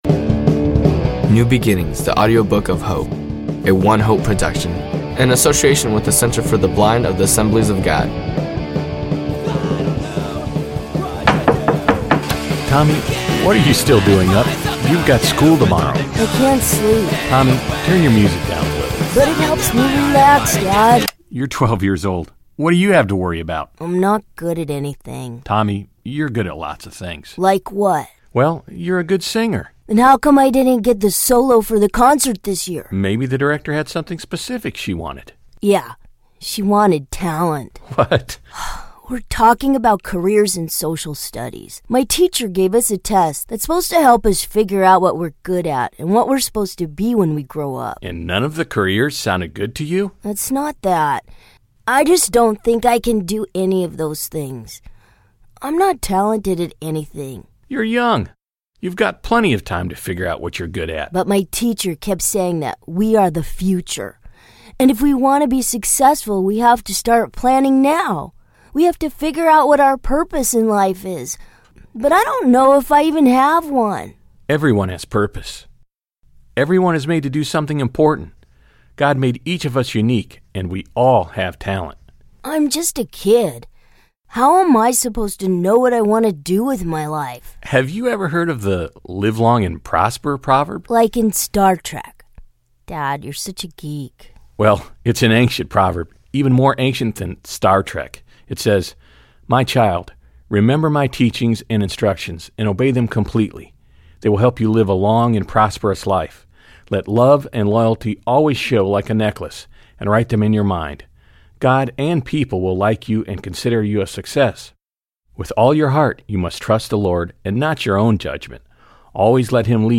The fictional audio story focuses on two visually impaired teens (one blind from birth, the other recently blinded in an accident). Through the story, the felt needs of of visually impaired youth are gently dealt with, including often having a "self-focus."